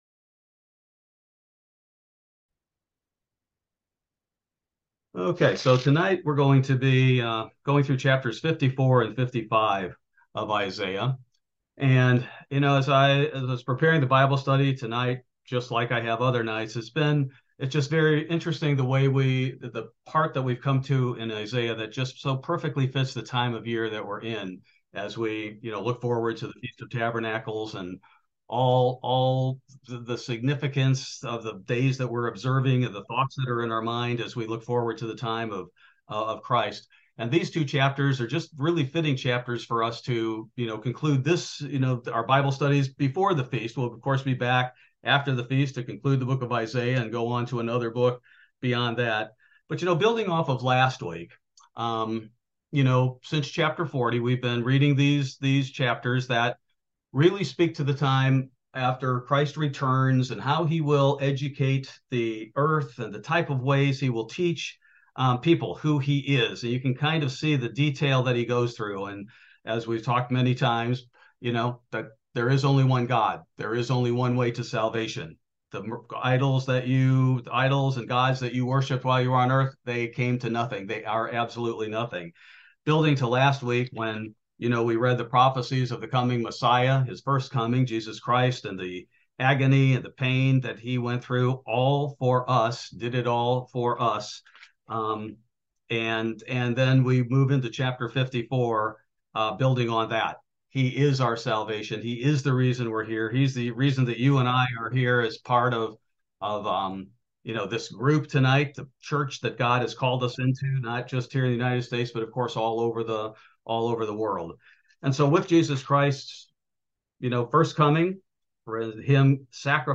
Bible Study: September 20, 2023